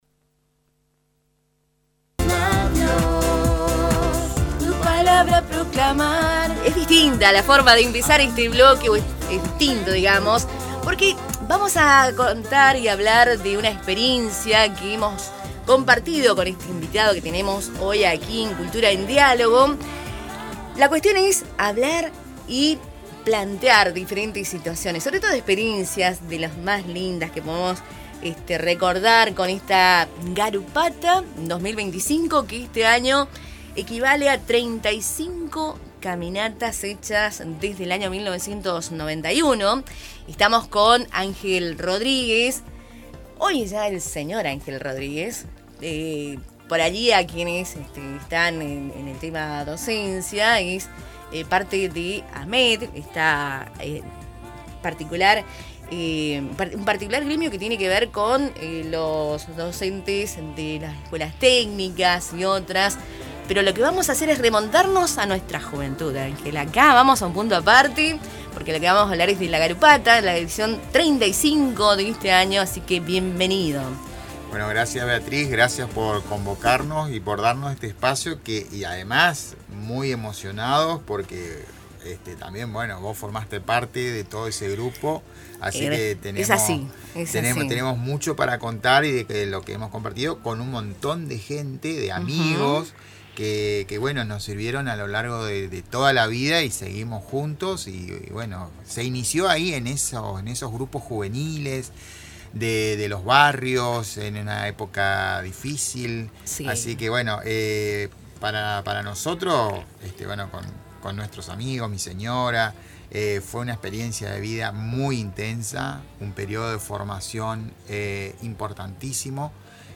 en el programa Cultura en diálogo, por Radio Tupa Mbae